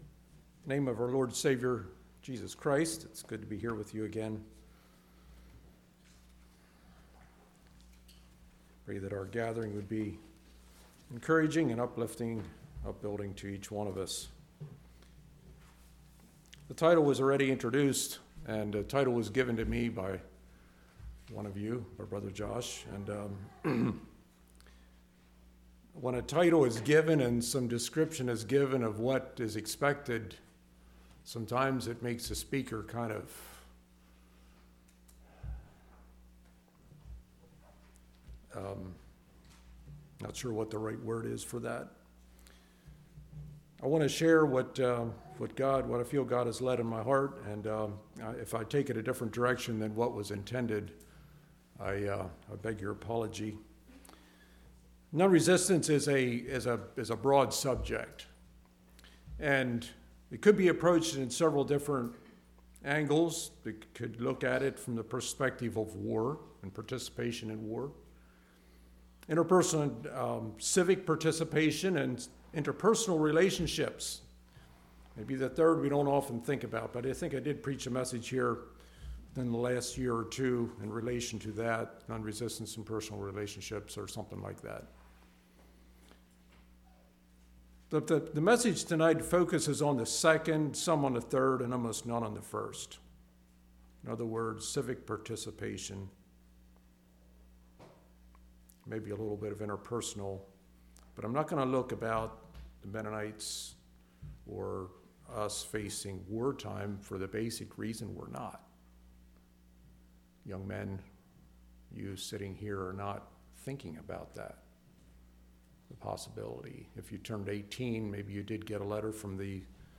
Congregation: Leola